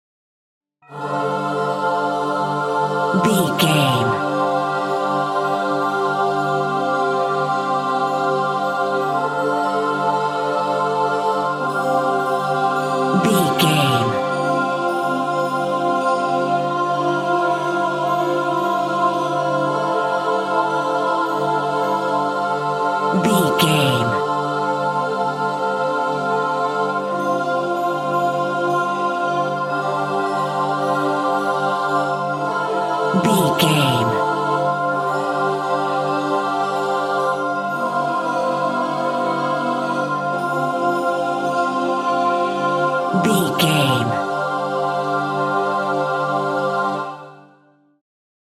Angels Choir Varied Chords
Sound Effects
Atonal
dreamy
calm